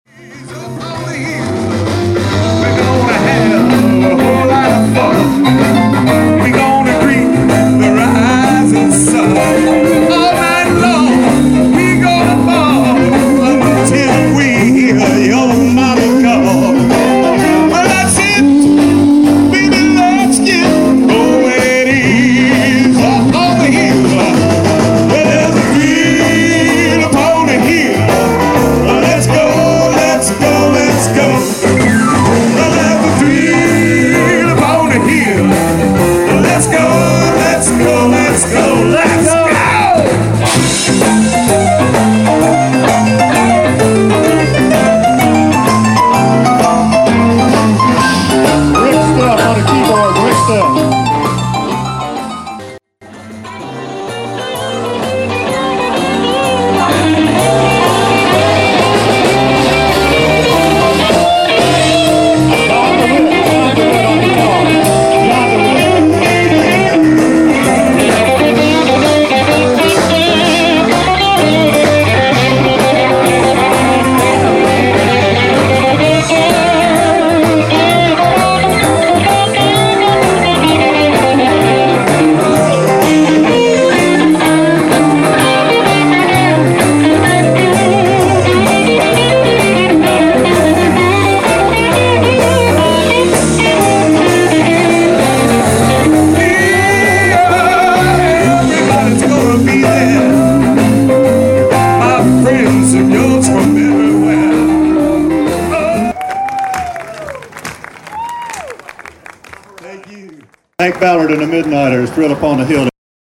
Live at Shangri-la